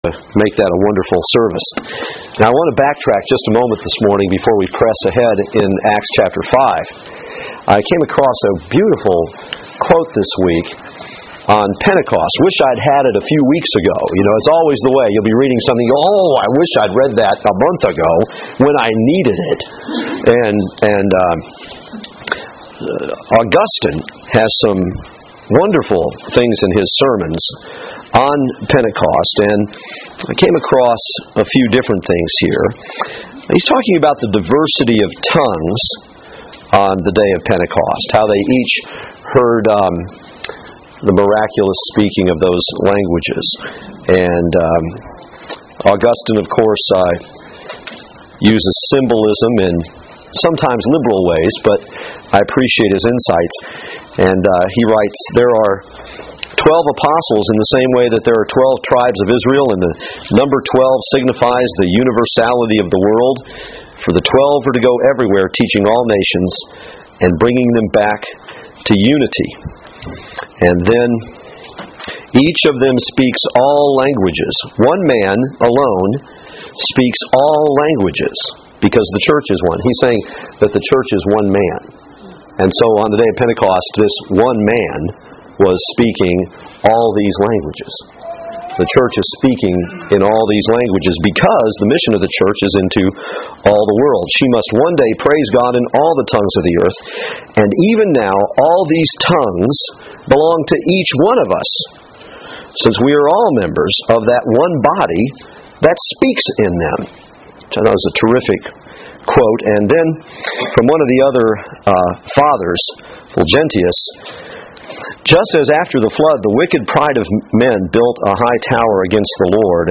Passage: Acts 3:1-6:7 Service Type: Women's Bible Study